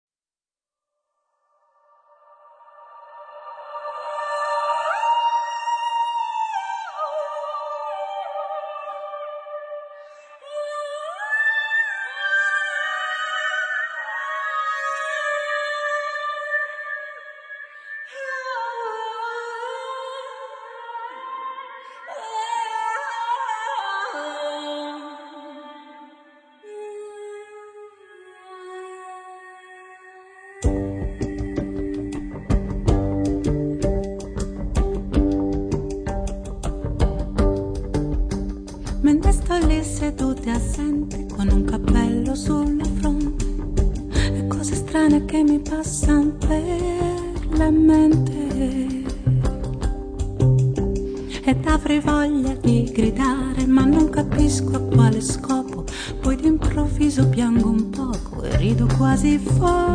voce, piano,fisarmonica, tastiere
contrabbasso
batteria e percussioni
C'è molta teatralità nel modo di cantare